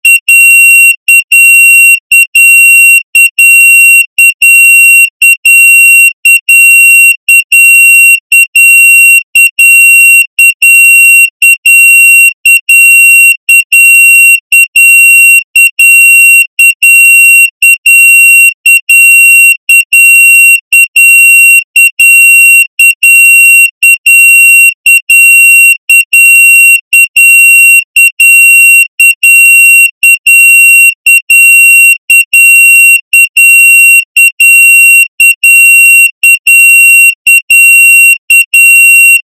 懐かしのポケベル風着信音。「ピピーピピー・・」でビープ音が鳴ります。